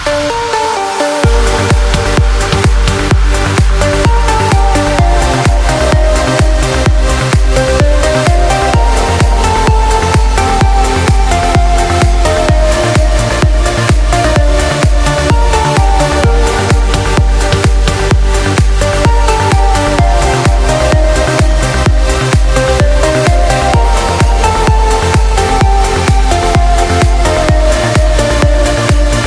• Качество: 143, Stereo
красивые
dance
Electronic
без слов
Trance
Отрывок советской мелодии в современной обработке.